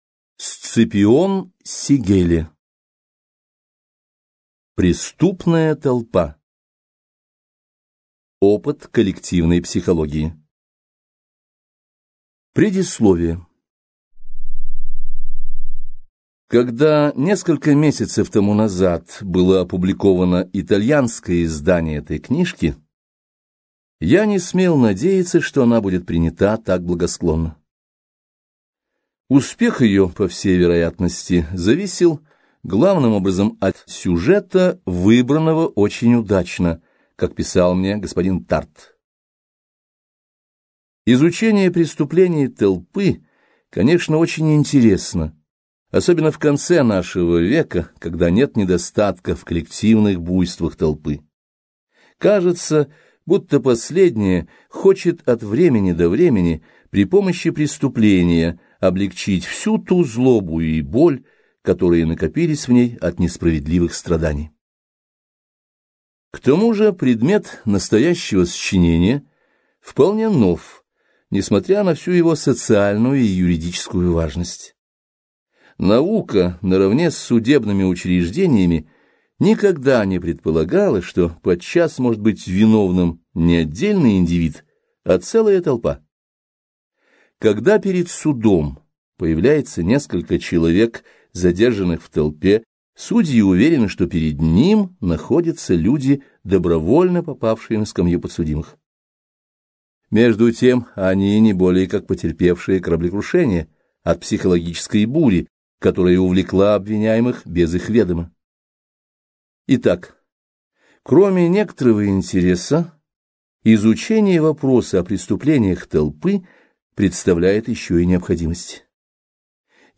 Аудиокнига Преступная толпа | Библиотека аудиокниг